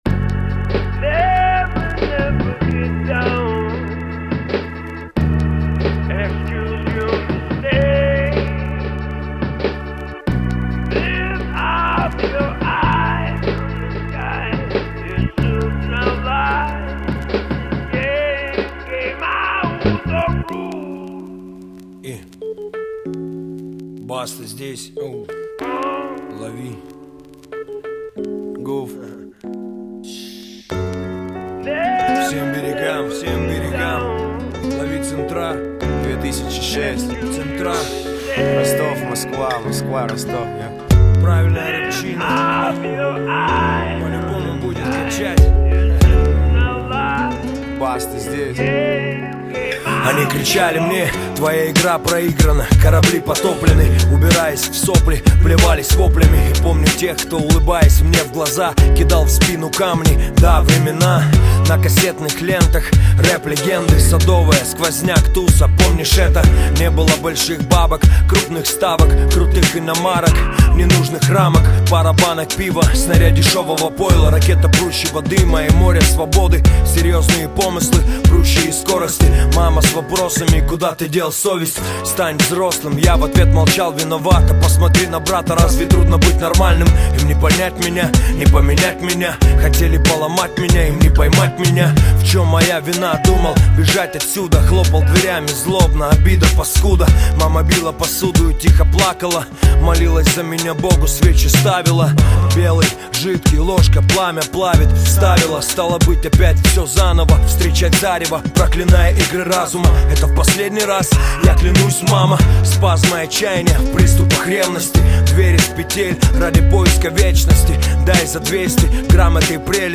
Категория: Русский рэп